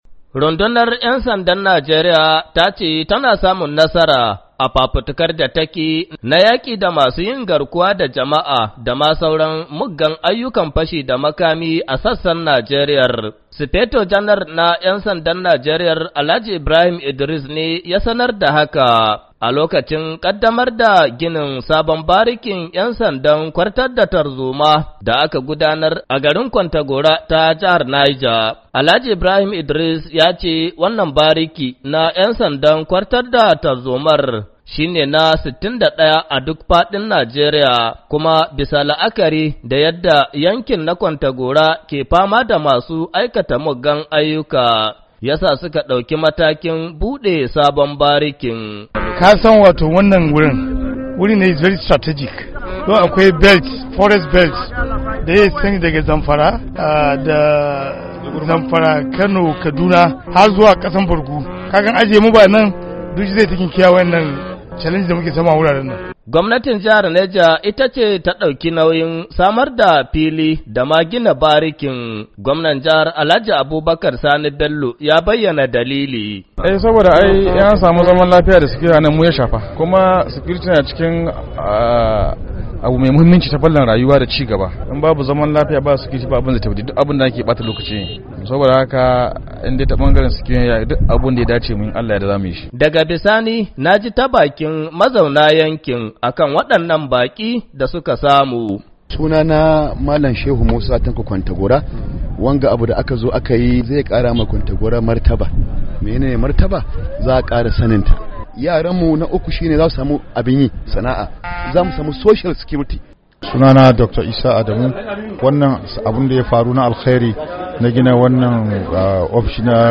Sufeto janal na rundunar ‘yan sandan Najeriya Alhaji Ibrahim Idriss ne ya bayyana hakan a wajen bukin kaddamar da ginin sabon barikin ‘yan sandan kwantar da tarzoma da aka gudanar a garin Kwantagora na jahar Neja.